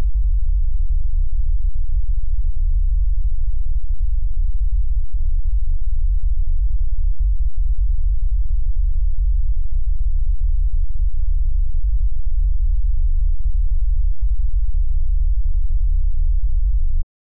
напруга канала ДЧТ с бодовой 96